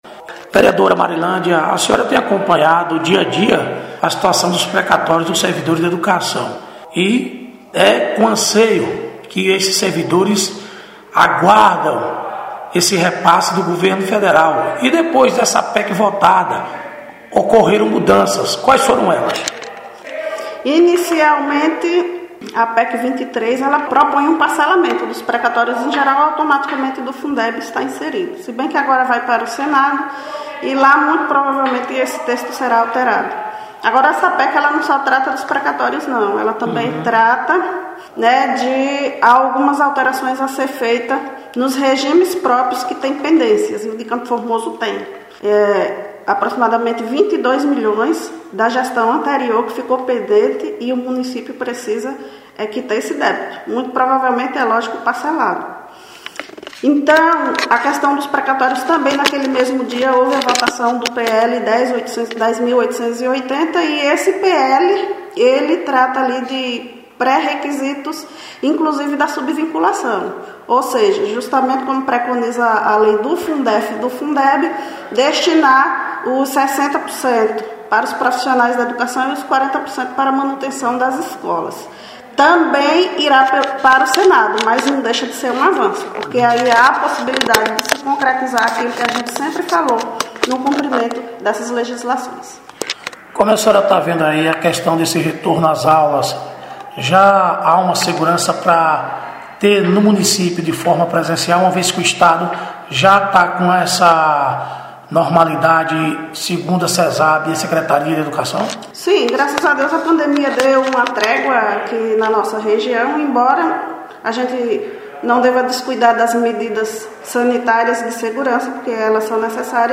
Reportagem- Vereadores de Campo Formoso